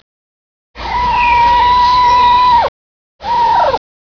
16 Titfer was making a strange singing sound whilst bathing in the sun.  Tried to get a recording, but not sure if it's a good one yet.
Titfer's singing.
TitferSong.wav